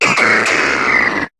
Cri de Quartermac dans Pokémon HOME.